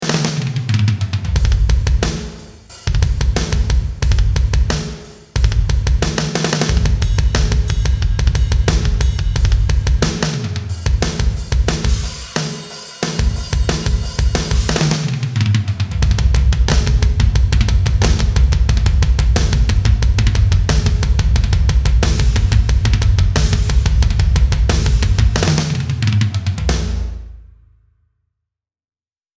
80S 鼓组，鼓室，鼓手
完美录制的80年代复古鼓组
综合式的律动库，80年代风格的节拍
所有鼓声都是录制自英国伦敦 Abbey Road Studios，并使用着现代化的设备以及录音技术。
声音类别: 原声鼓